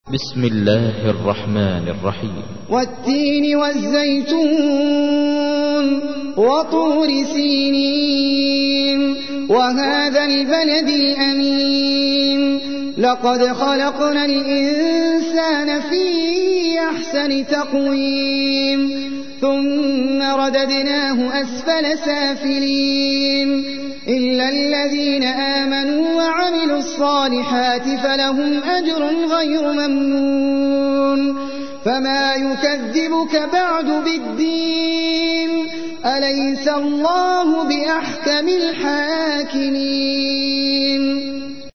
تحميل : 95. سورة التين / القارئ احمد العجمي / القرآن الكريم / موقع يا حسين